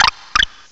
cry_not_deerling.aif